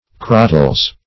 Search Result for " crottles" : The Collaborative International Dictionary of English v.0.48: Crottles \Crot"tles\ (kr?t"t'lz), n. pl.